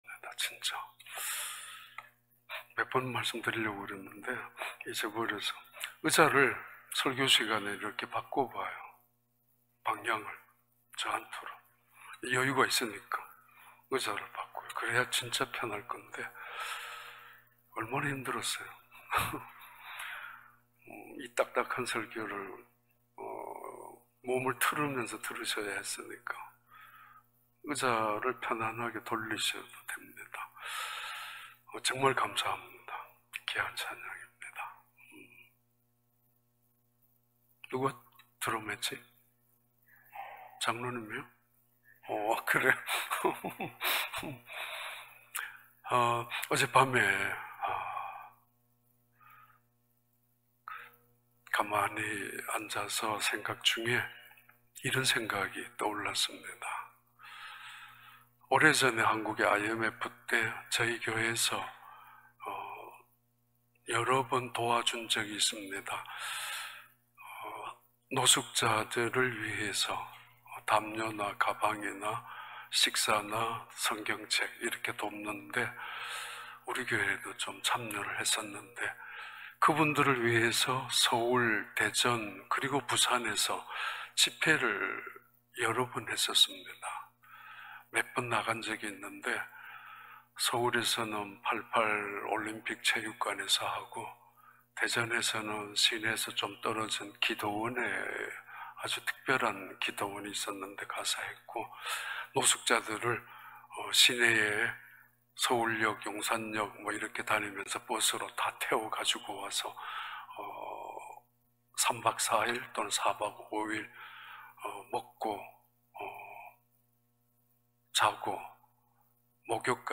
2021년 4월 25일 주일 4부 예배